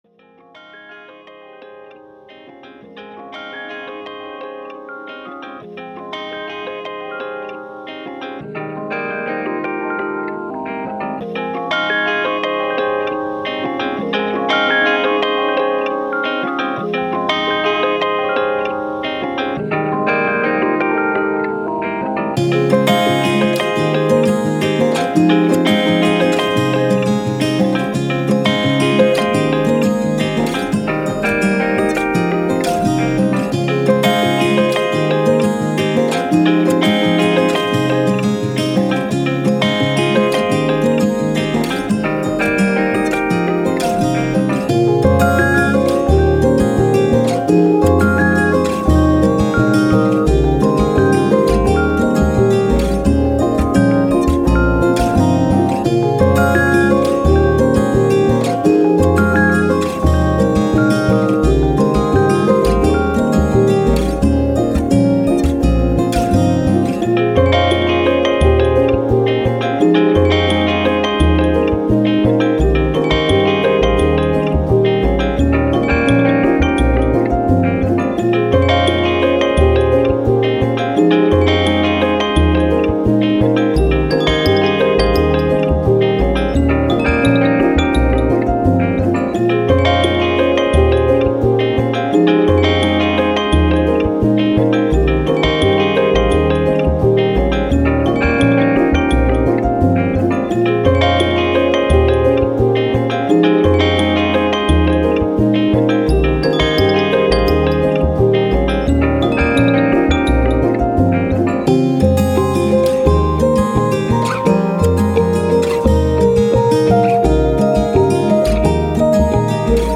Soundtrack, Ambient, Piano, Thoughtful, Emotive, Positive